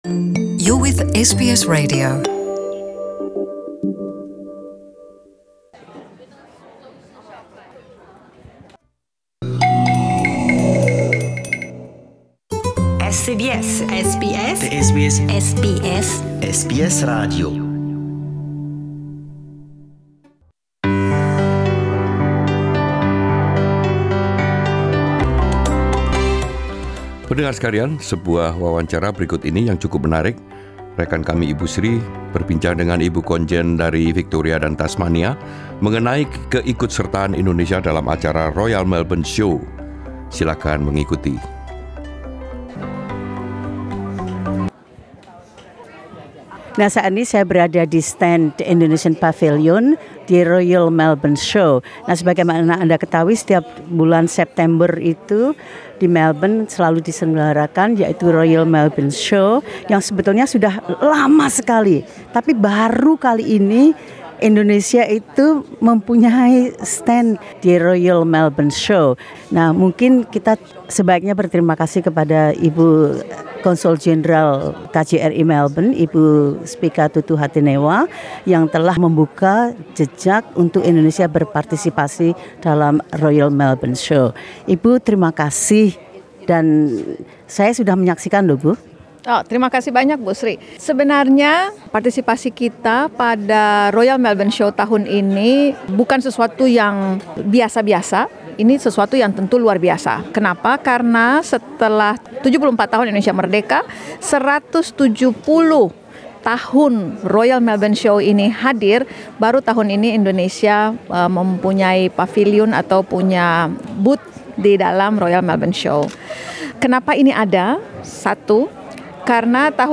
Di paviliun Indonesia di Royal Melbourne Show, Konsul Jenderal Indonesia untuk Victoria dan Tasmania, Spica A.Tutuhatunewa menjelaskan mengapa Konsulat Jenderal akhirnya memutuskan untuk terlibat dalam acara tersebut.